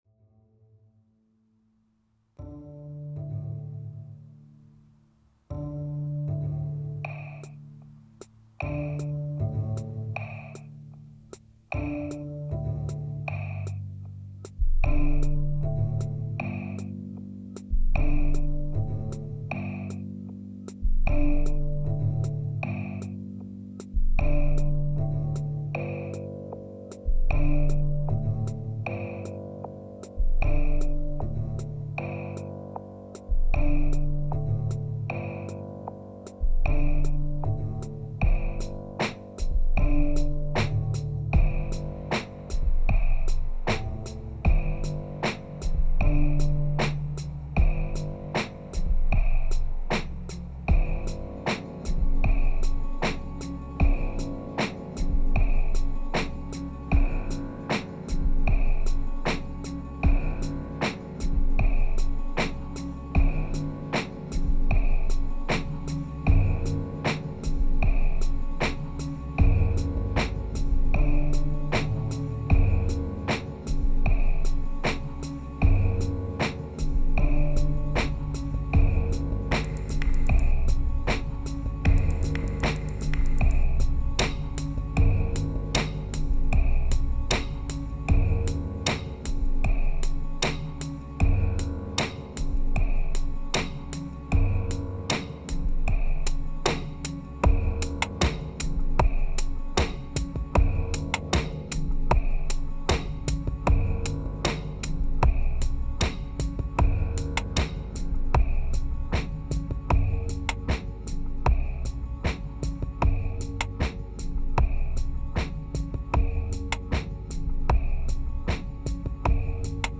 2653📈 - 30%🤔 - 77BPM🔊 - 2017-09-09📅 - -78🌟